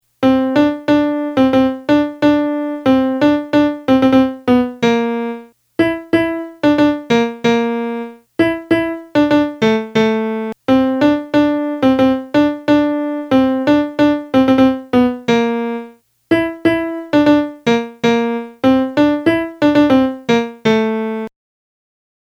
da-milan-fin-a-turin-melody.mp3